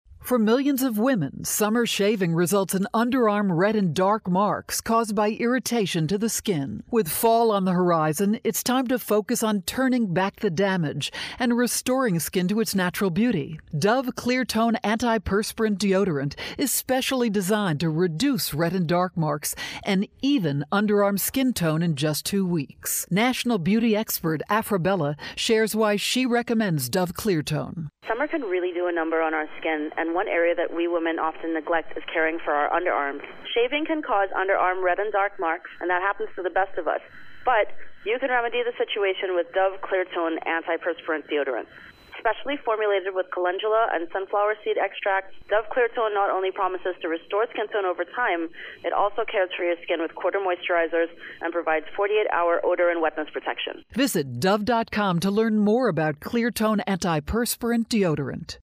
September 3, 2013Posted in: Audio News Release